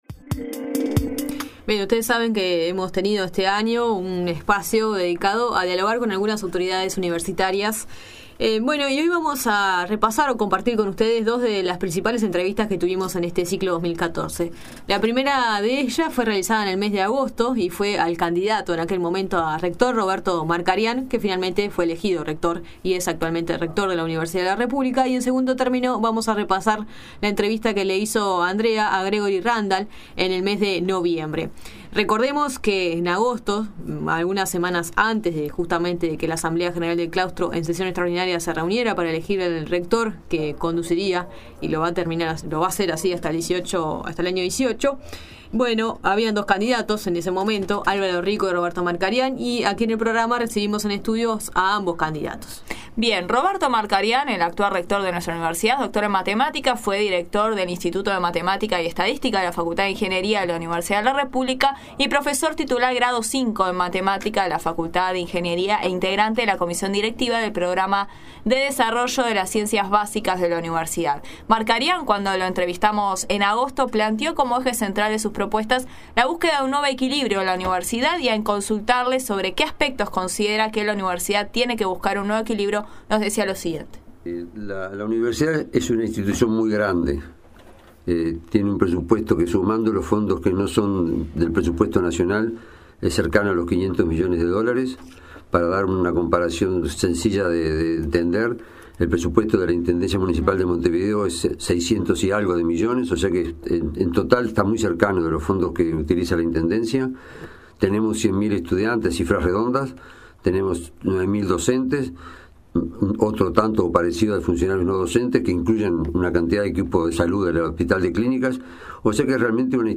Audio: Repaso Decanos, entrevistas al Dr. Roberto Markarian y al Dr. Gregory Randall